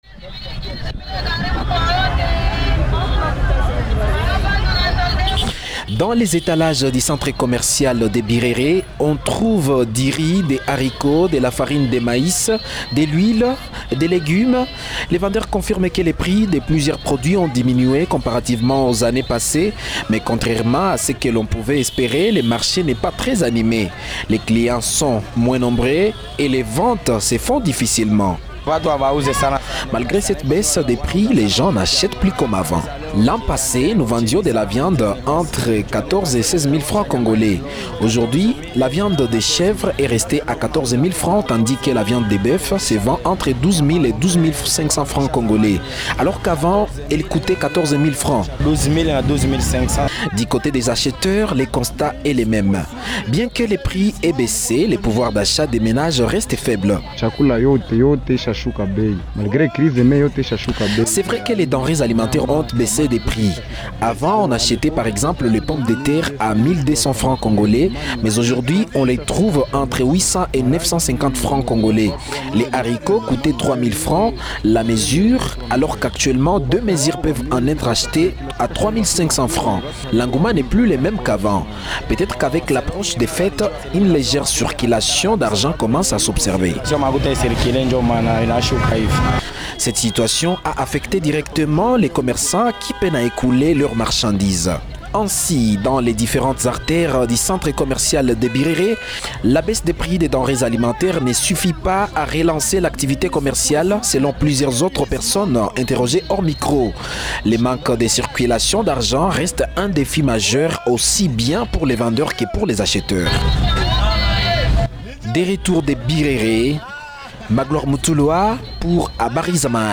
« L’an passé, la viande se vendait entre 14 et 16 mille francs congolais. Aujourd’hui, la viande de chèvre est restée à 14 mille francs, et celle de bœuf est descendue entre 12 et 12 mille 500 francs, mais les clients ne viennent plus comme avant », témoigne un vendeur, interrogé au micro de Habari za Mahali.
« Les pommes de terre, par exemple, se vendent aujourd’hui entre 800 et 950 francs, alors qu’elles coûtaient 1 200 francs auparavant. Le haricot aussi a baissé, mais l’engouement n’est plus le même », confie un client rencontré sur place.